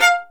Index of /90_sSampleCDs/Roland L-CD702/VOL-1/STR_Violin 1-3vb/STR_Vln2 % + dyn